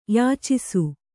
♪ yācisu